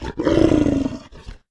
pdog_idle_0.ogg